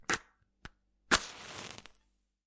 火柴棒
描述：使用火柴盒。